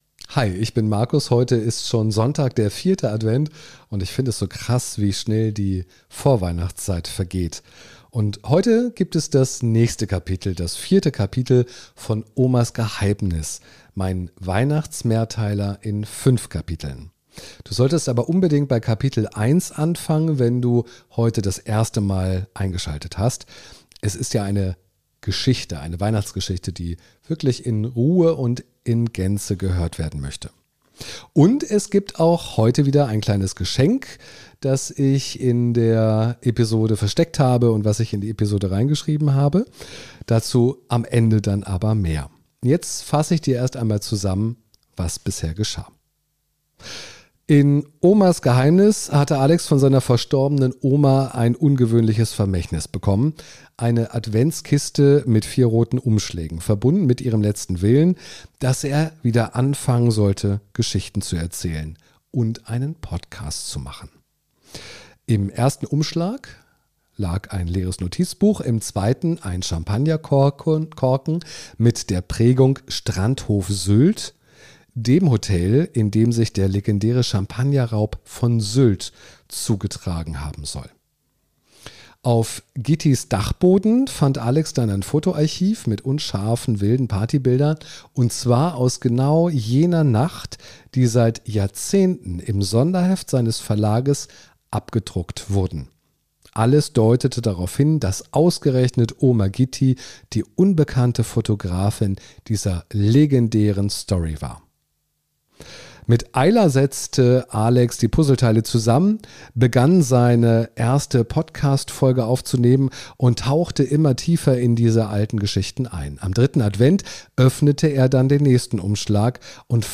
Warmherzig, atmosphärisch und geheimnisvoll erzählt – ideal für eine Tasse Tee, ein Stück Rotweinkuchen und ein bisschen Adventsmagie.